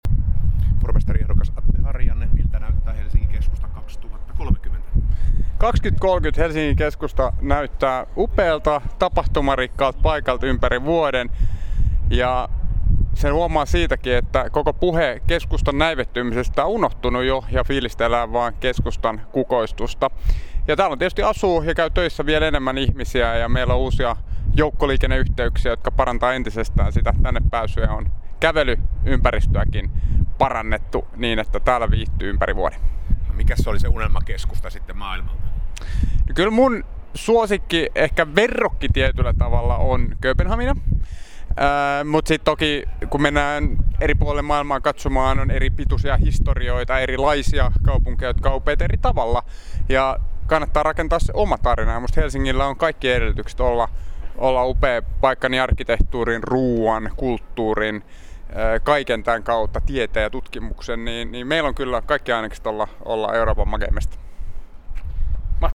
Pormestaripaneelissa Sofia Helsingissä kuultiin 26.3. ehdokkaita.